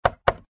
GUI_knock_3.ogg